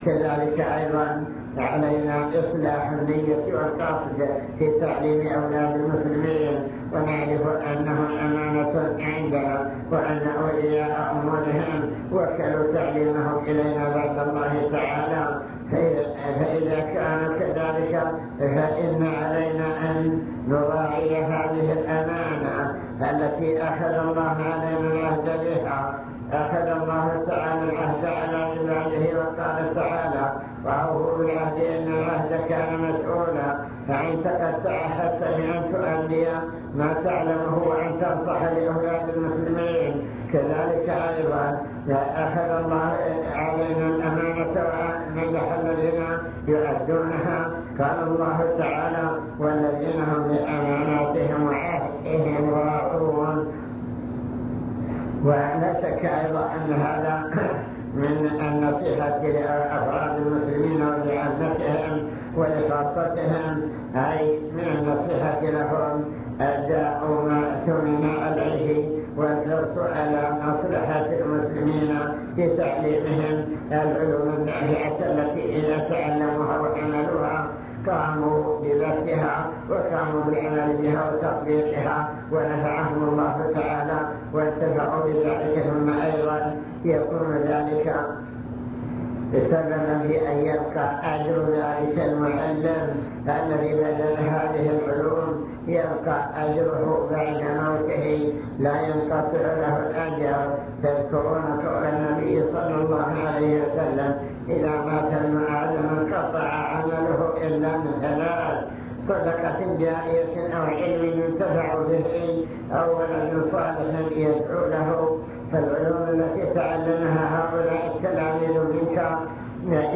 المكتبة الصوتية  تسجيلات - لقاءات  لقاء إدارة التعليم